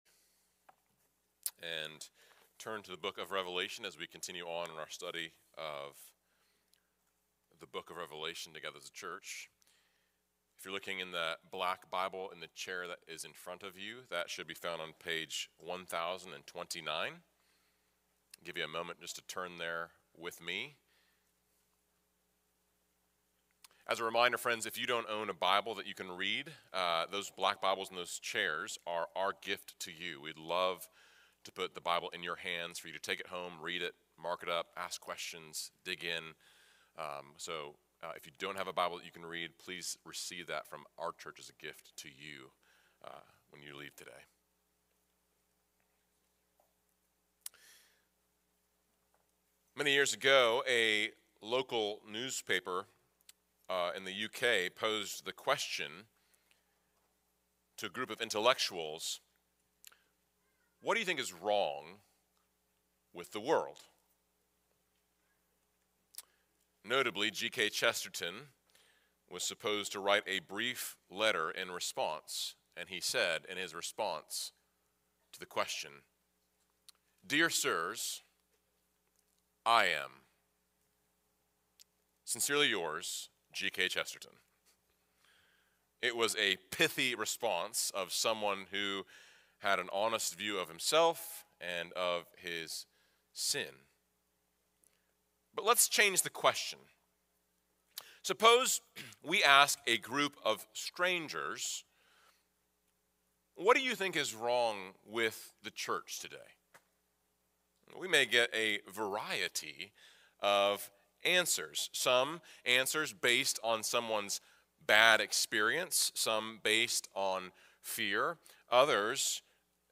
Home - FBC Sermons